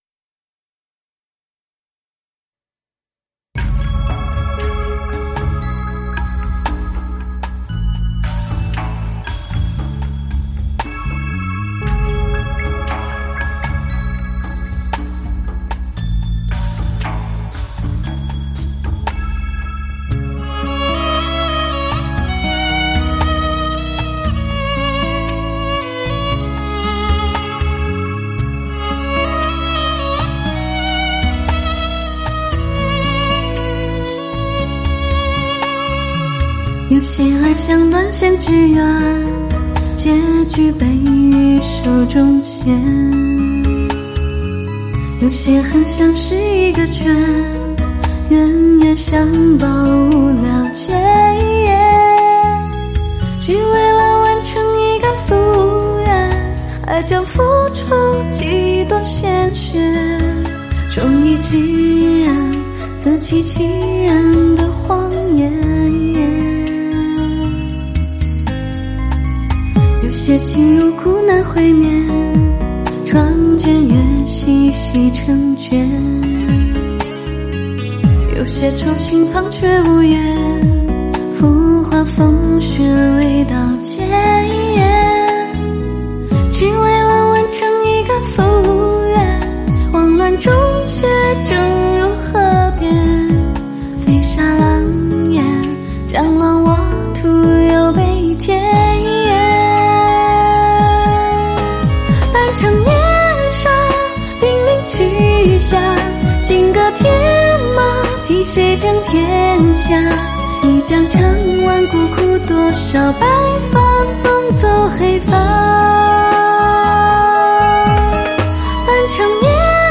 顶级女声 倾情演绎
最富声色吸引力的流行HIFI热曲
完美迷人的真空录制-问鼎立体音效